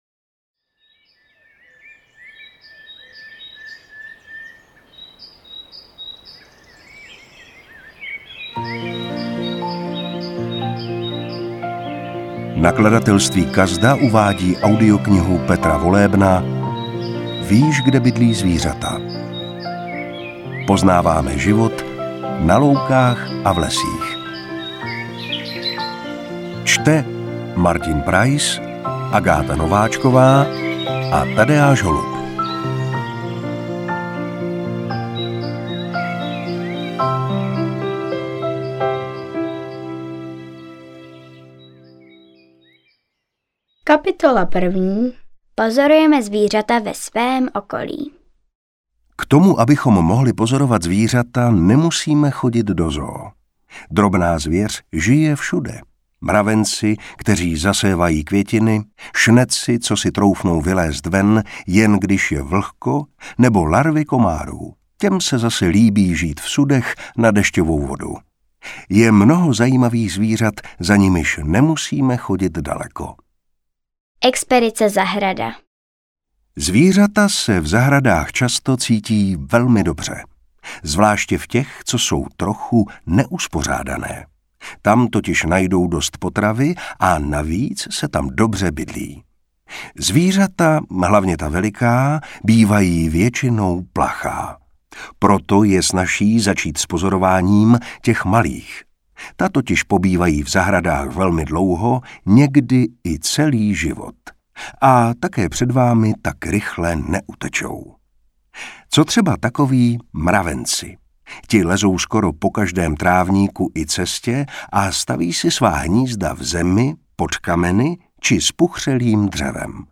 Interpret:  Martin Preiss
AudioKniha ke stažení, 11 x mp3, délka 3 hod. 18 min., velikost 363,6 MB, česky